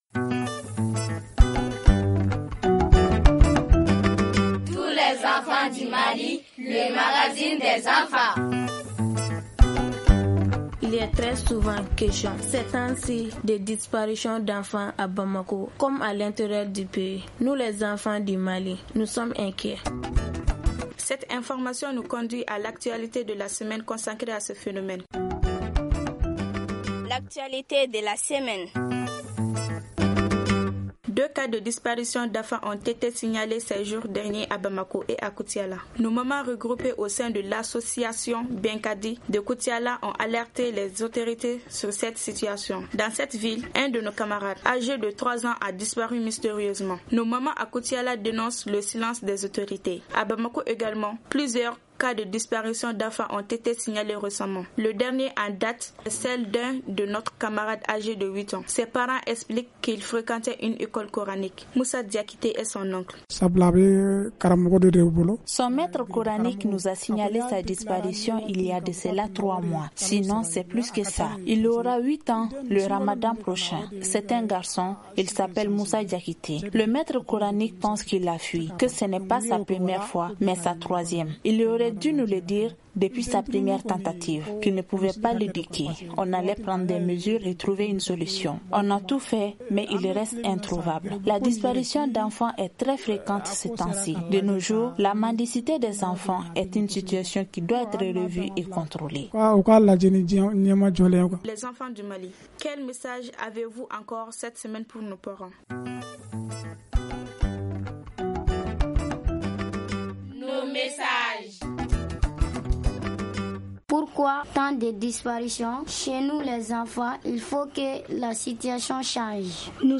présente par un groupe d’enfants